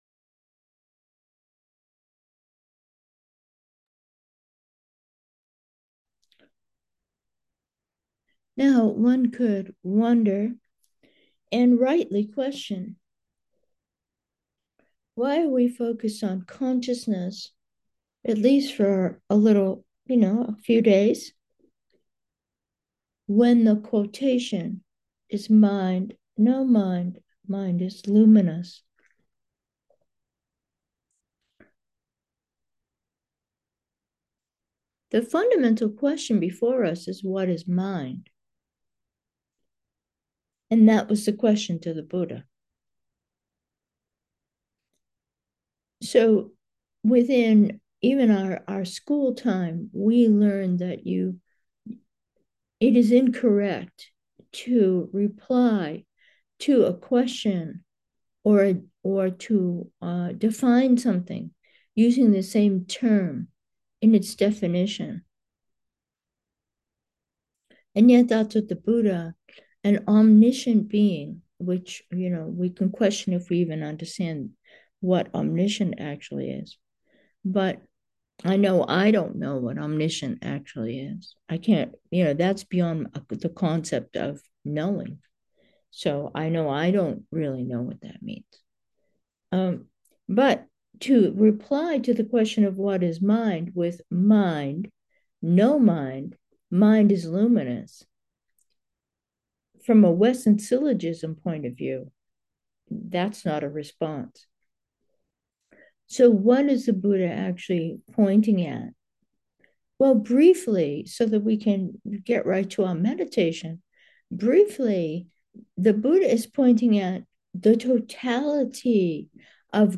Meditation: pixels